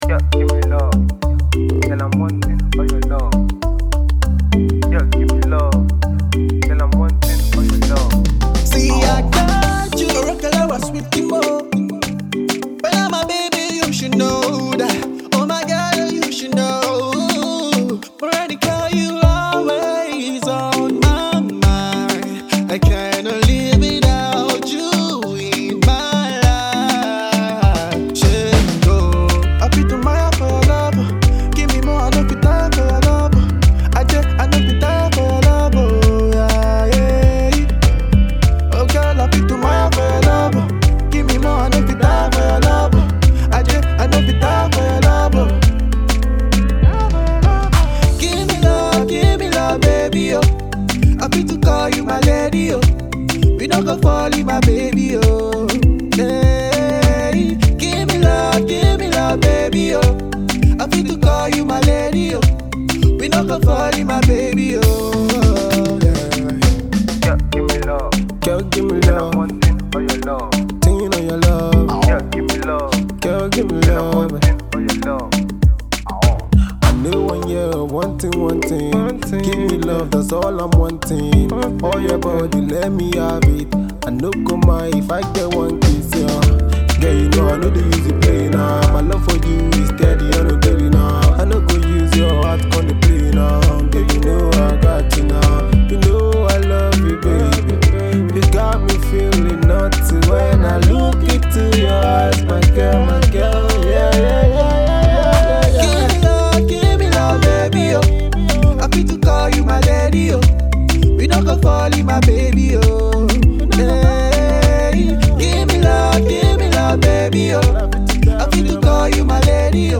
classic afro love sound